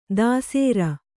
♪ dāsēra